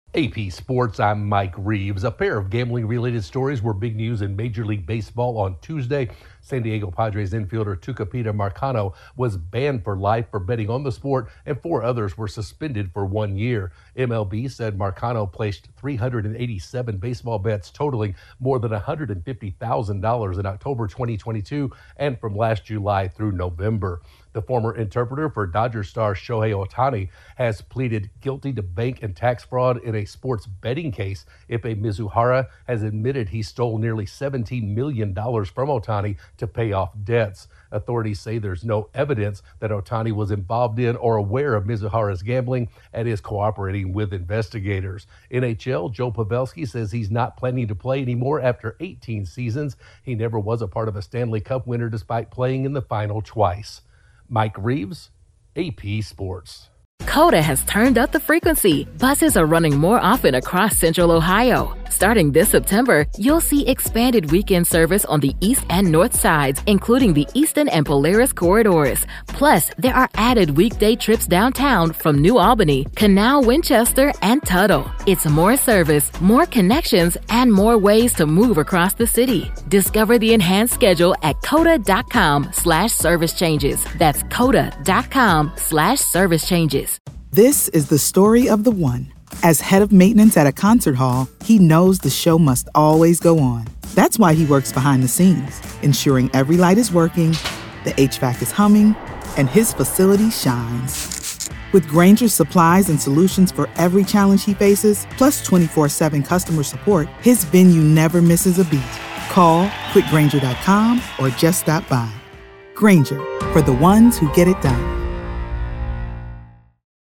Major League Baseball hands down a lifetime suspension to one of its players, a former interpreter for a Dodgers star pleaded guilty to charges, and a long time National Hockey League star hangs up his skates. Correspondent